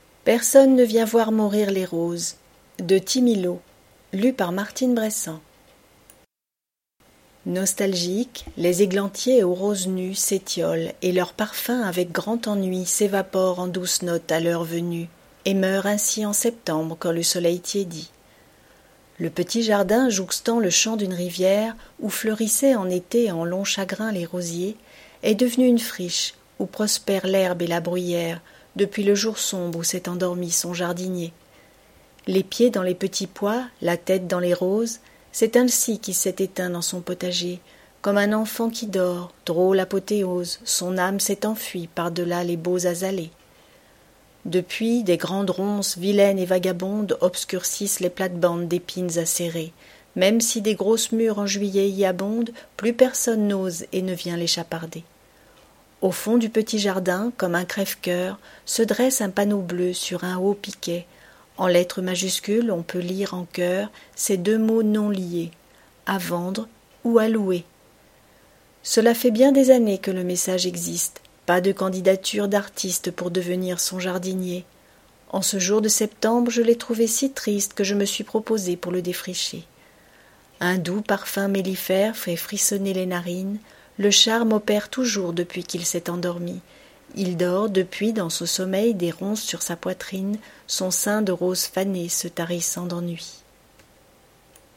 Lecture à haute voix - Personne ne vient voir mourir les roses
POEME